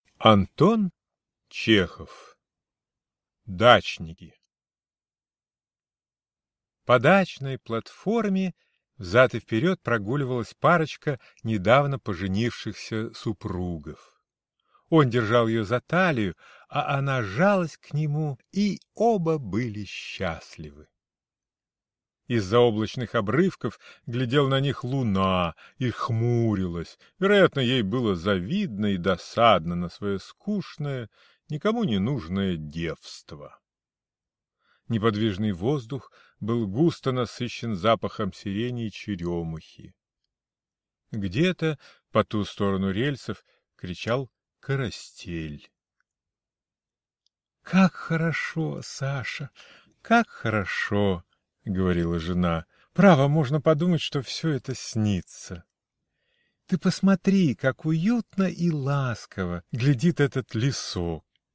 Аудиокнига Дачники | Библиотека аудиокниг